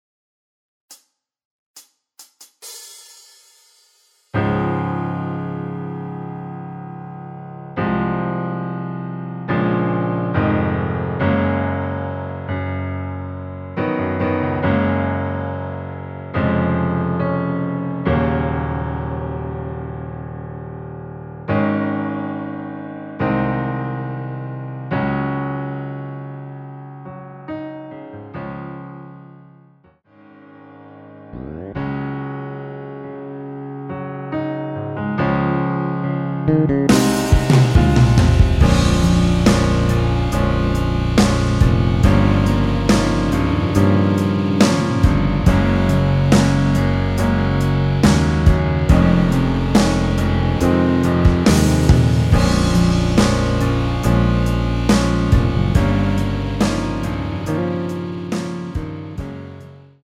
전주없이 노래가 시작되는 곡이라 카운트 만들어 놓았습니다.
원키에서(-3)내린 MR입니다.
Db
앞부분30초, 뒷부분30초씩 편집해서 올려 드리고 있습니다.
중간에 음이 끈어지고 다시 나오는 이유는